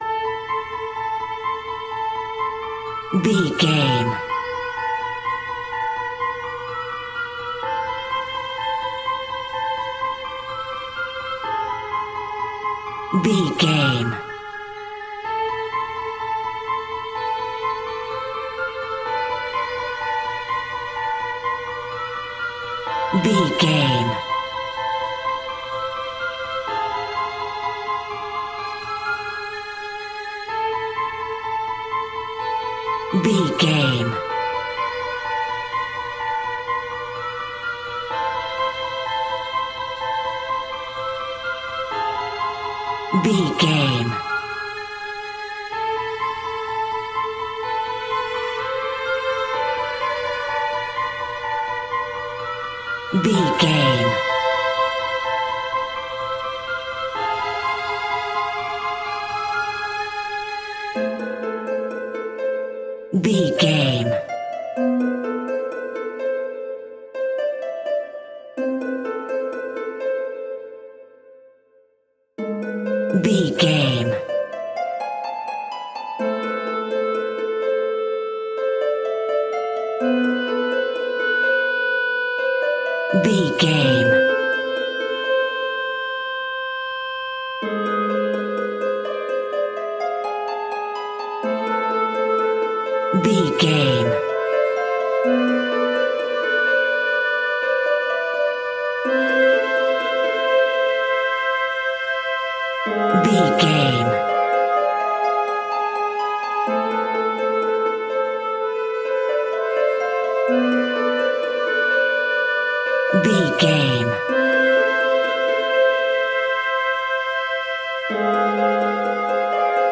Aeolian/Minor
tension
ominous
dark
suspense
haunting
eerie
piano
strings
synth
ambience
pads